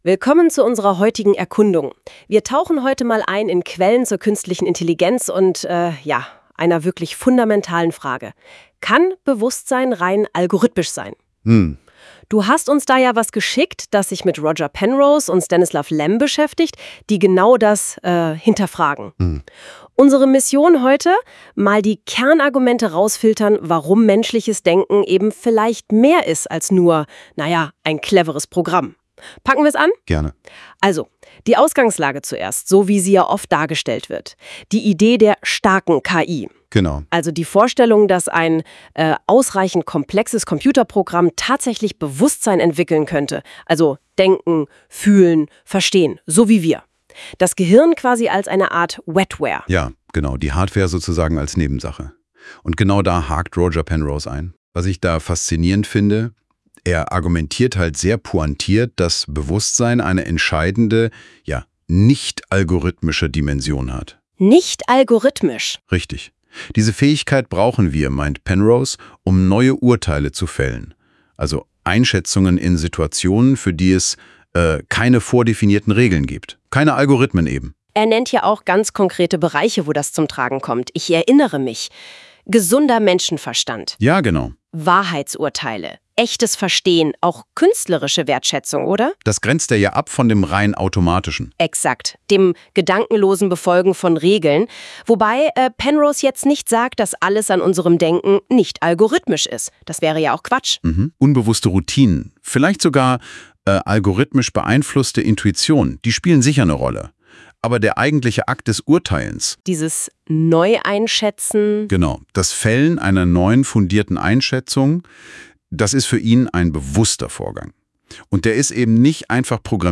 Hier noch als automatisch erstellter Podcast